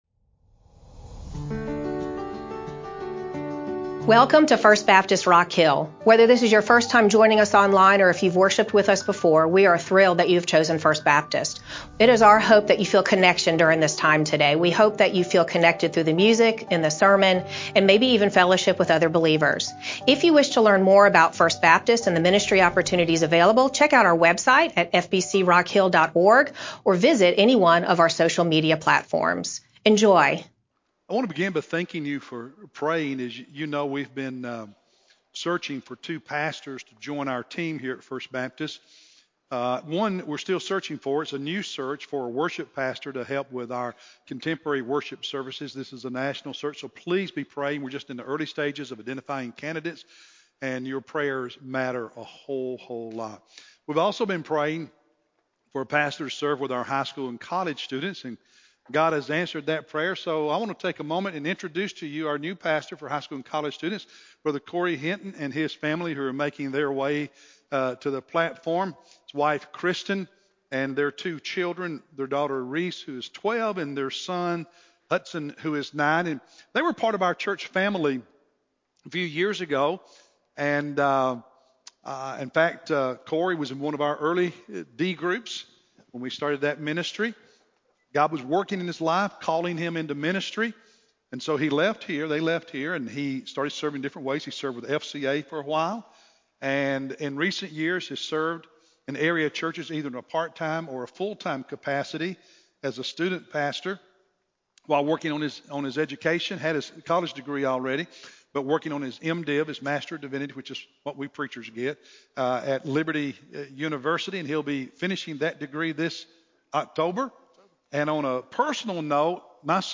Feb-8-sermon-CD.mp3